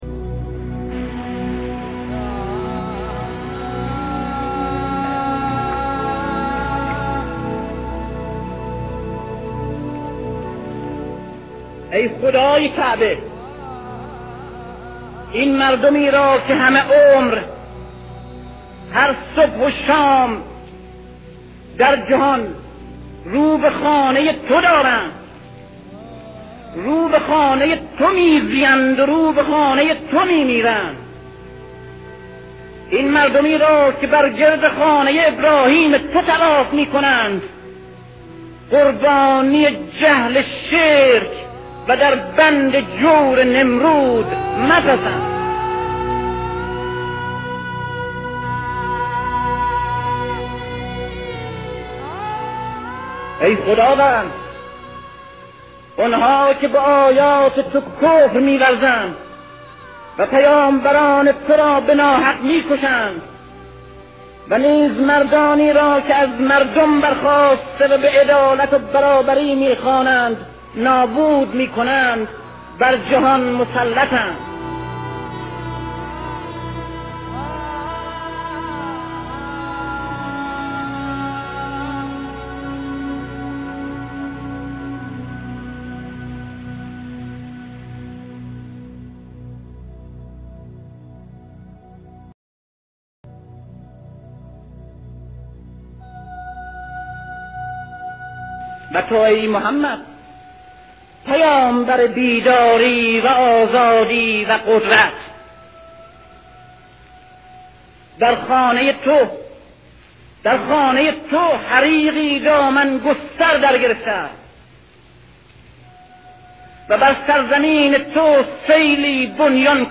صوت | «از کدامین قبیله‌ای؟» با صدای علی شریعتی